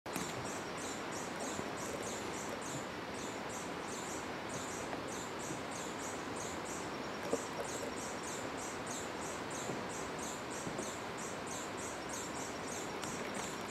Picaflor Copetón (Stephanoxis loddigesii)
Nombre en inglés: Purple-crowned Plovercrest
Fase de la vida: Adulto
Localidad o área protegida: Parque Provincial Salto Encantado
Condición: Silvestre
Certeza: Vocalización Grabada